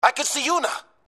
Sex: Male